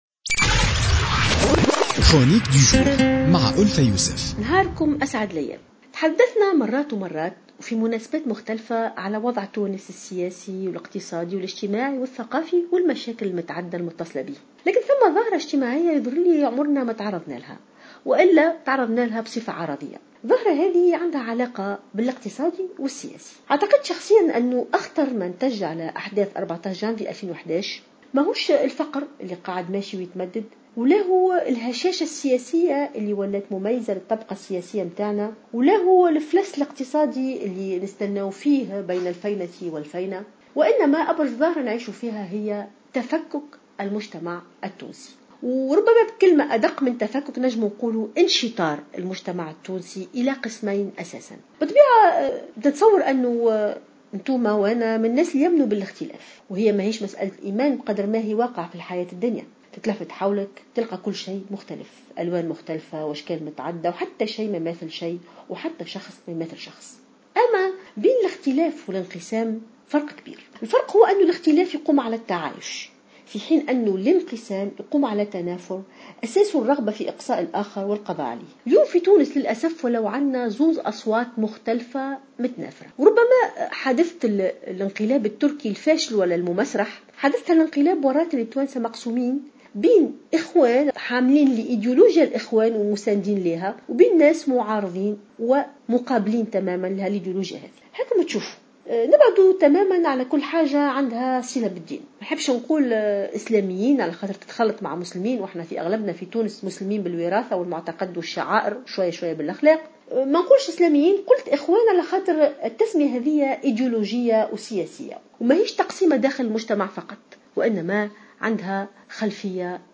قالت الجامعية ألفة يوسف في افتتاحية اليوم الأربعاء إن أخطر ما تواجهه تونس كنتاج لأحداث 14 جانفي 2011، ما وصل إليه المجتمع من تفكّك و"انشطار".